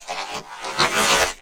MONSTERS_CREATURES
ALIEN_Communication_07_mono.wav